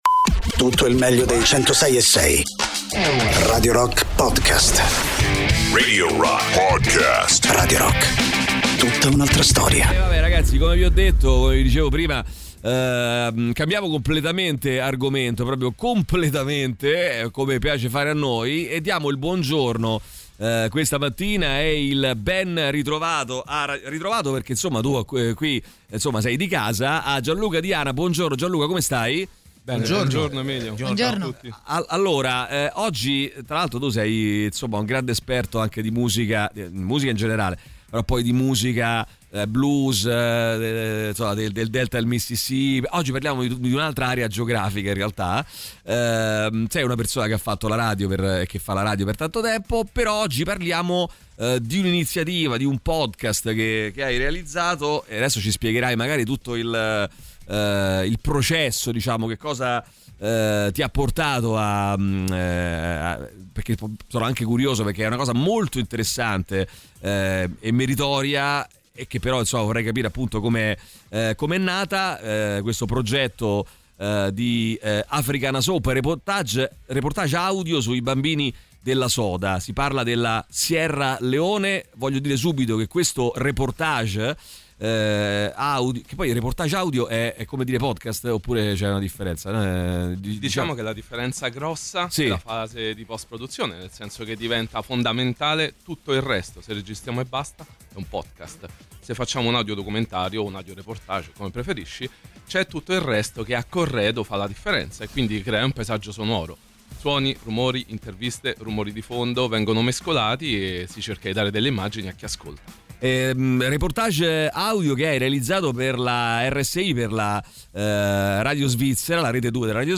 Interviste
ospite in studio